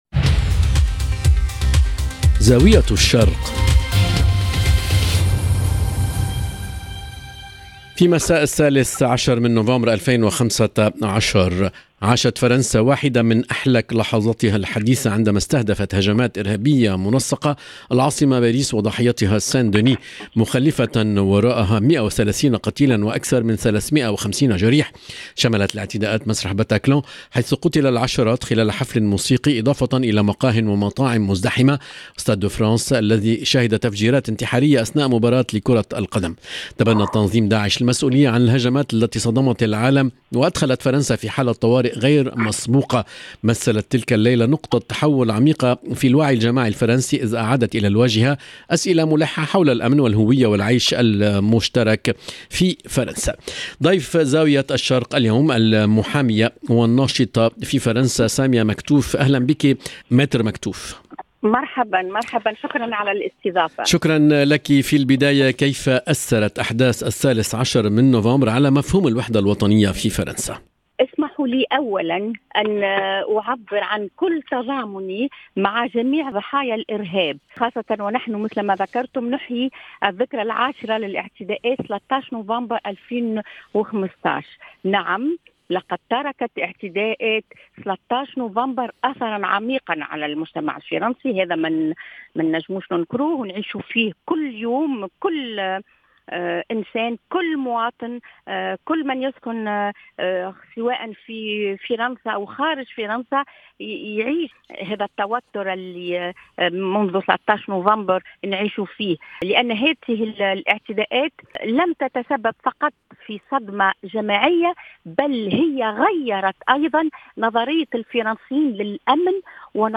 اللقاء